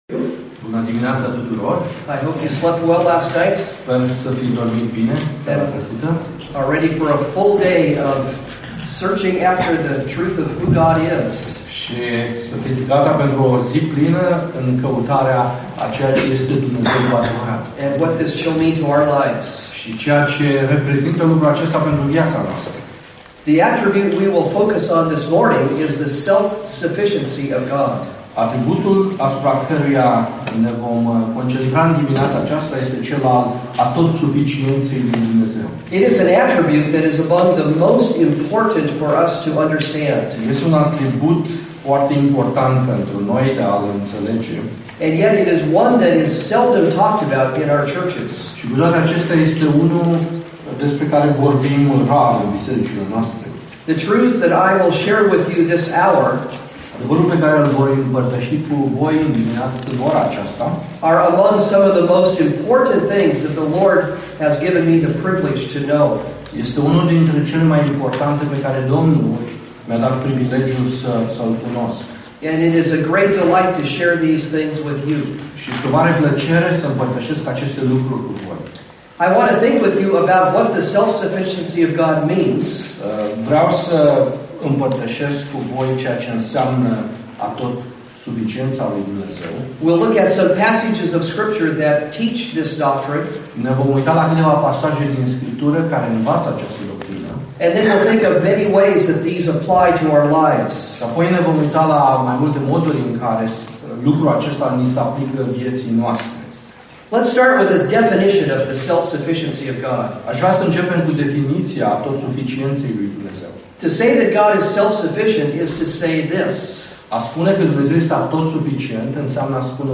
Predici Complete